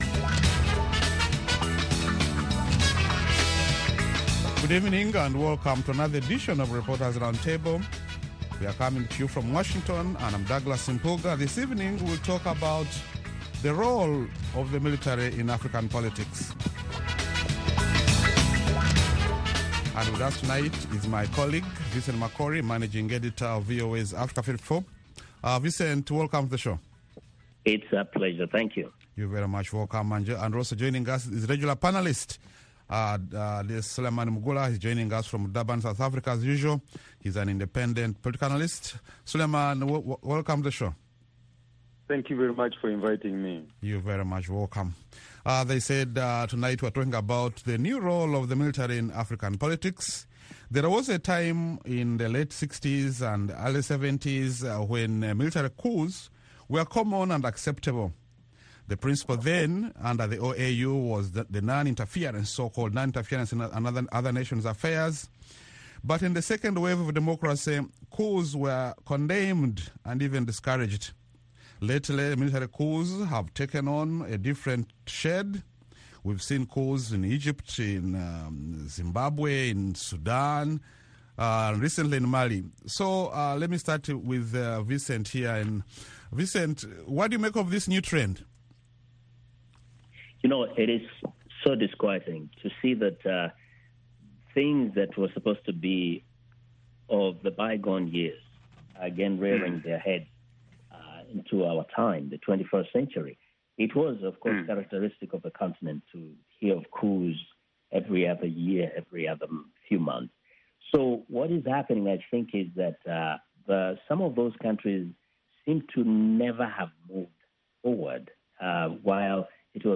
along with a lively panel of journalists, who analyze the week’s major developments in Africa.